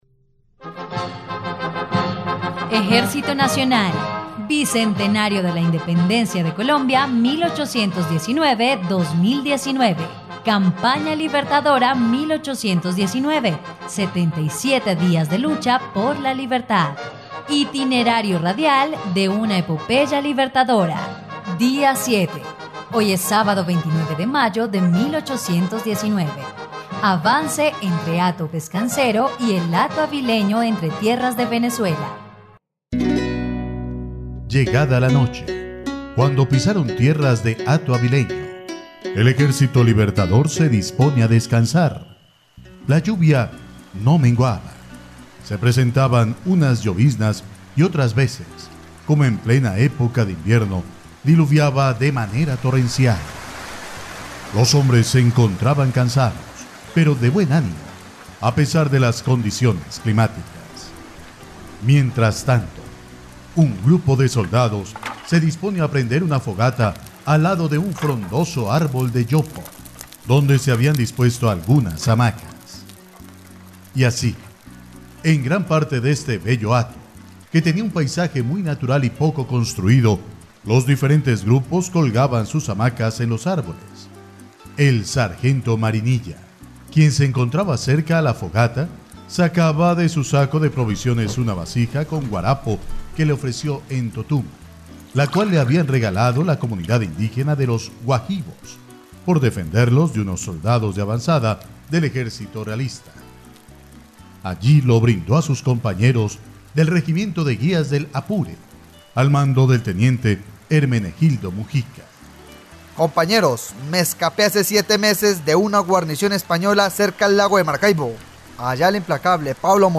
dia_07_radionovela_campana_libertadora.mp3